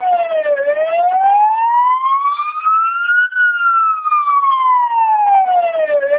In contrast to other LBR vocoders (MELPe, AMBE+2, etc.), TWELP vocoders provide high quality of non-speech signals, including police, ambulance, fire sirens, etc. This feature in conjunction with high quality natural human-sounding of voice makes TWELP vocoders well suitable for replacement of analog radio by digital radio and also for other applications where high quality transmitting of non-speech signals is relevant along with high quality transmitting of speech signals.
signal  AMR-NB
You can hear that the TWELP vocoder processes non-speech signals just as well, if not more accurately, than the waveform AMR-NB codec.
amr_475_siren.wav